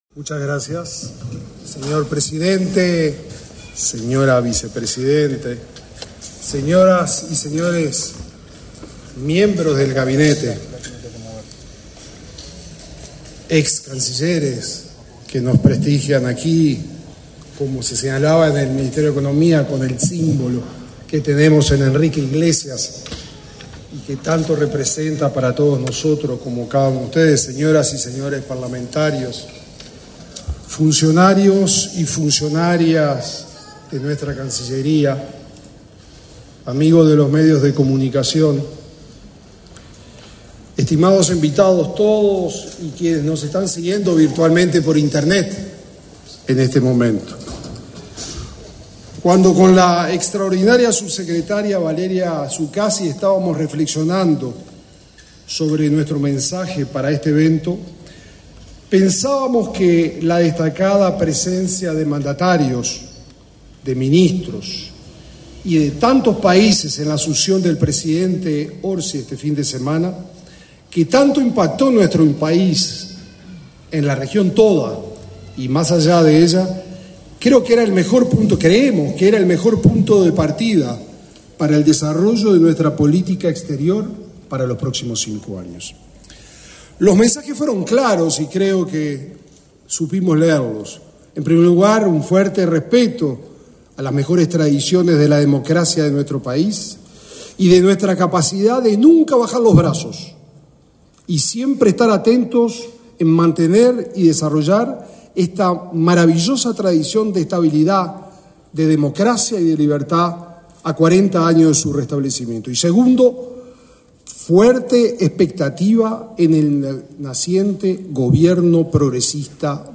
Palabras del ministro de Relaciones Exteriores, Mario Lubetkin
Palabras del ministro de Relaciones Exteriores, Mario Lubetkin 05/03/2025 Compartir Facebook X Copiar enlace WhatsApp LinkedIn Con la presencia del presidente de la República, Yamandú Orsi, y la vicepresidenta, Carolina Cosse, se realizó, este 5 de marzo, el acto de asunción del ministro de Relaciones Exteriores, Mario Lubetkin, y de la subsecretaria, Valeria Csukasi.